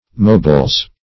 Search Result for " moebles" : The Collaborative International Dictionary of English v.0.48: Moebles \Moe"bles\, n. pl.